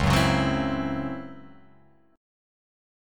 C#mM7bb5 Chord